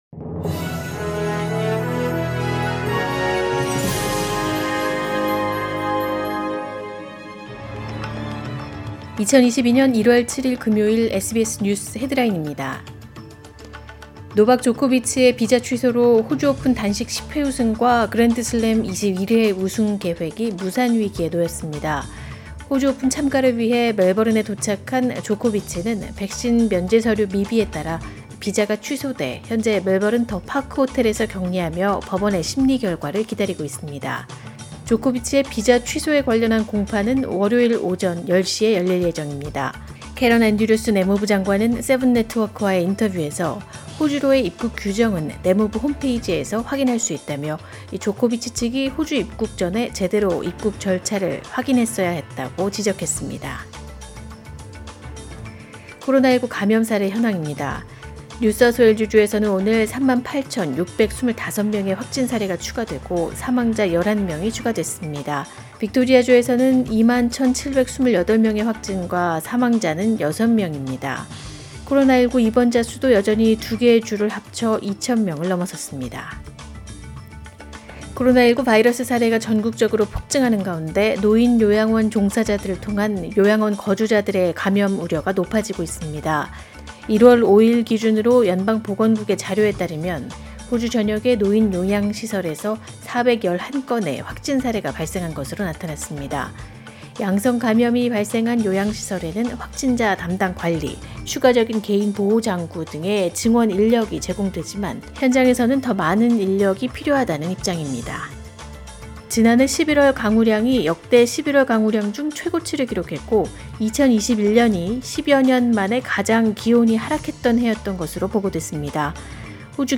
2022년 1월 7일 금요일 오전의 SBS 뉴스 헤드라인입니다.